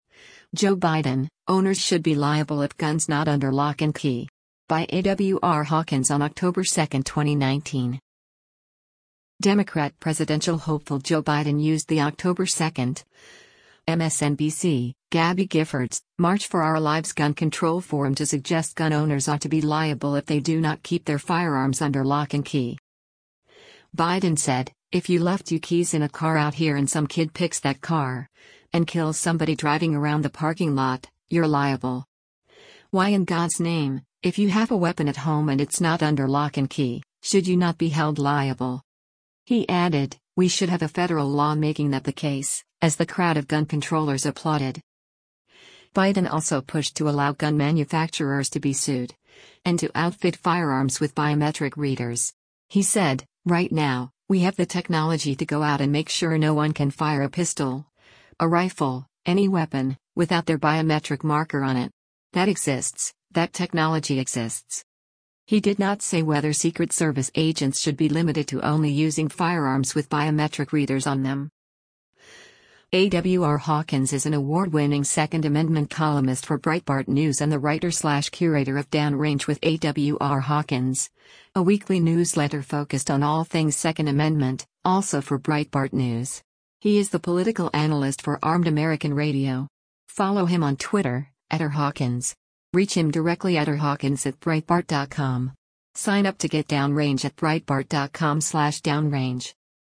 Democrat presidential hopeful Joe Biden used the October 2, MSNBC / Gabby Giffords / March for Our Lives gun control forum to suggest gun owners ought to be liable if they do not keep their firearms “under lock and key.”
He added, “We should have a federal law making that the case,” as the crowd of gun controllers applauded.